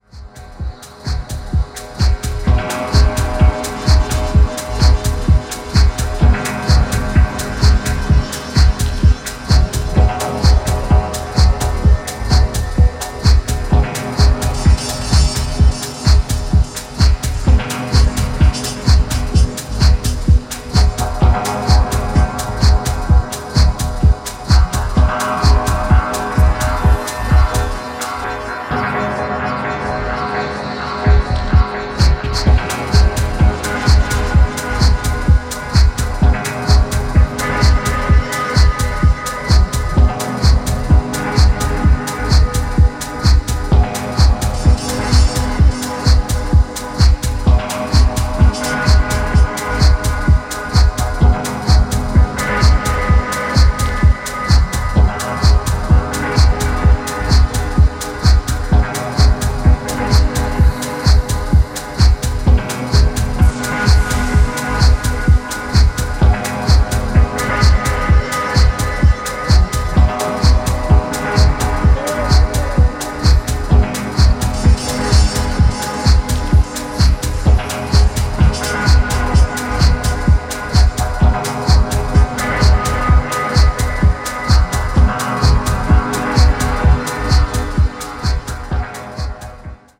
金属質の残響が心地良いです。
とことん、渋い一枚。